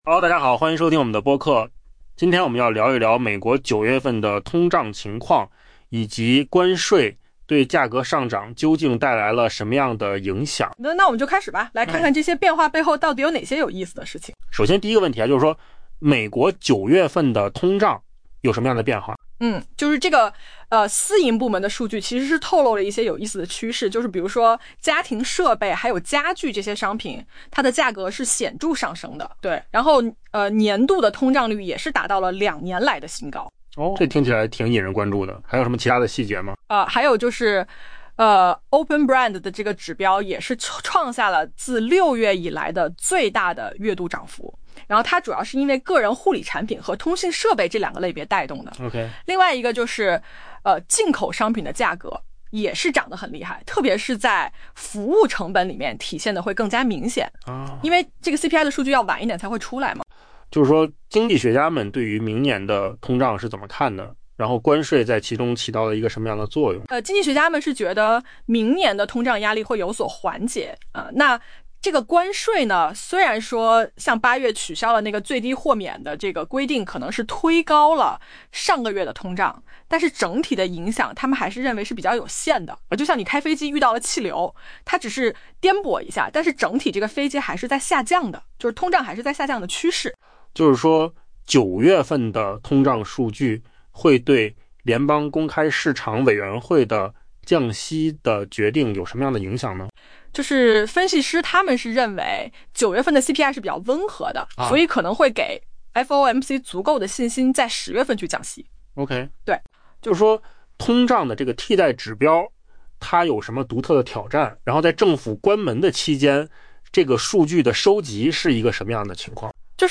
AI 播客：换个方式听新闻 下载 mp3 音频由扣子空间生成 私营部门的指标显示， 美国 9 月份通胀有所回升 ，在缺乏官方政府指标的情况下，为关税推高某些价格提供了更多证据。